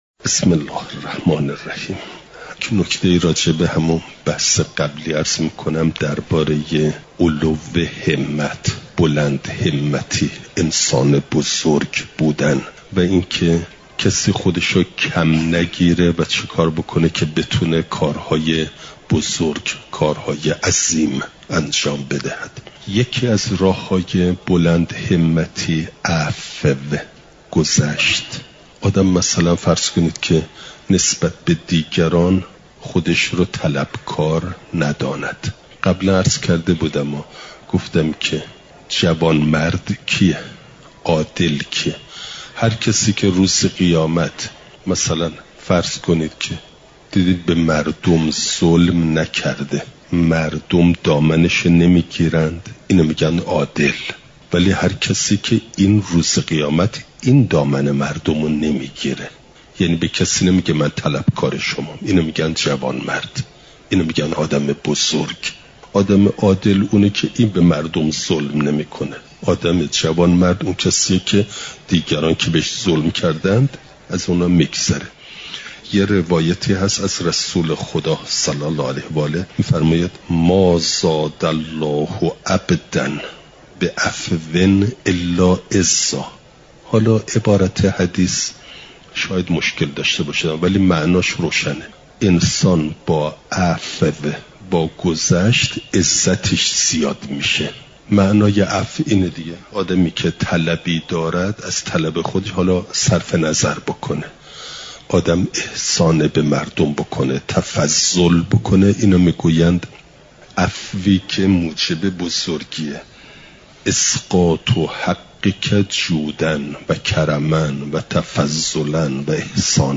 چهارشنبه ۲۶ آذرماه ۱۴۰۴، حرم مطهر حضرت معصومه سلام ﷲ علیها